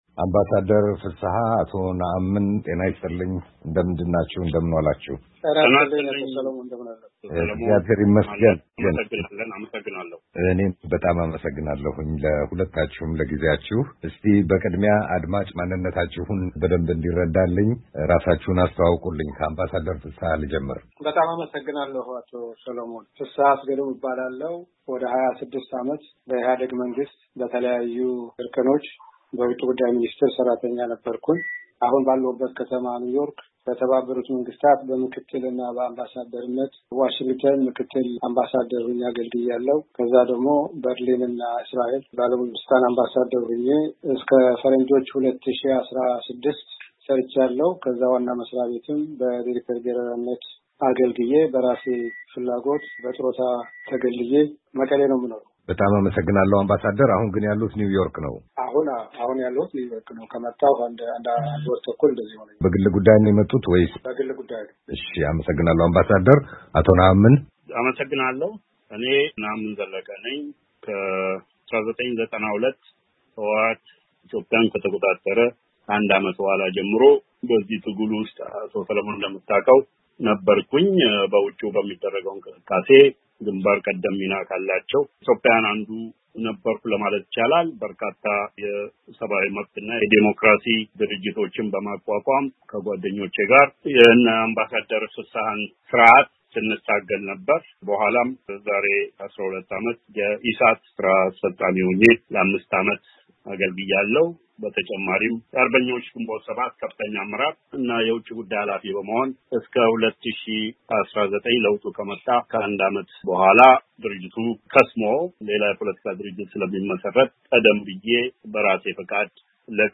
ክርክር በኢትዮጵያ የዛሬ ሁኔታ ላይ